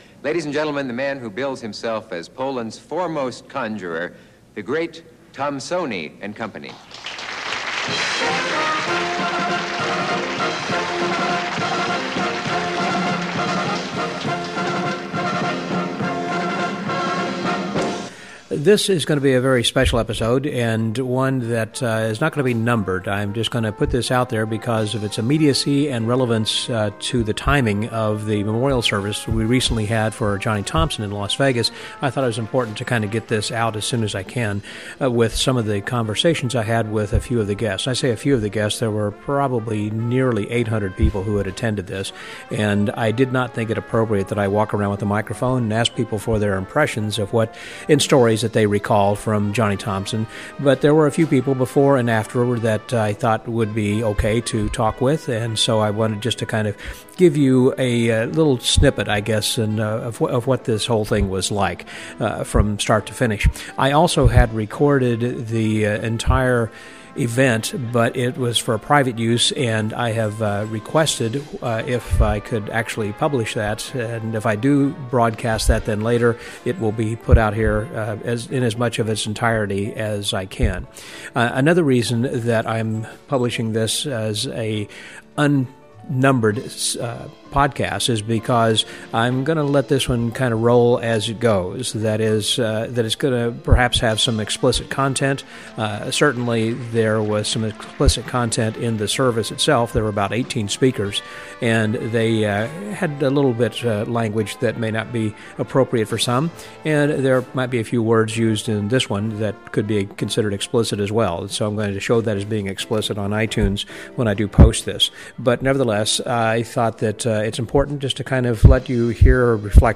This is an unedited, explicit recording of a few friends of Johnny Thompson who reminisced about their old friend.